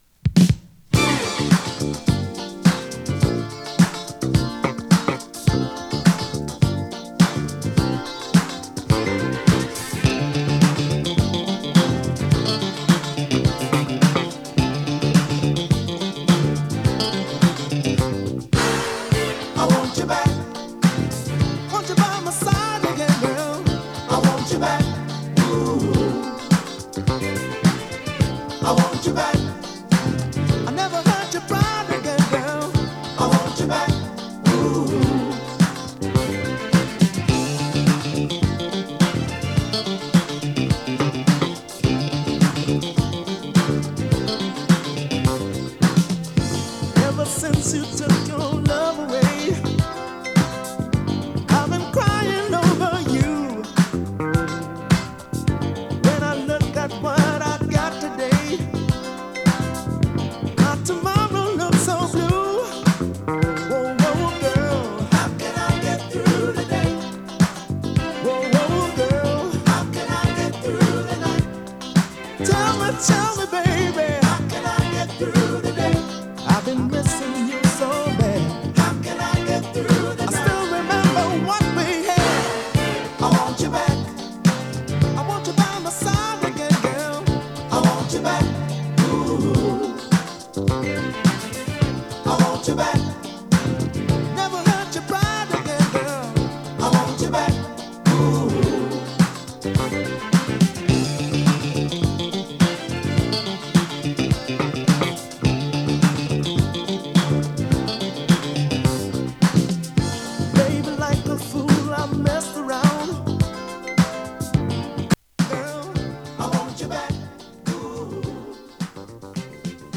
＊音の薄い部分で時折軽いチリパチ・ノイズ。